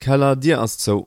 basementdoor_closed.mp3